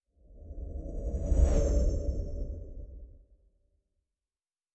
Distant Ship Pass By 5_3.wav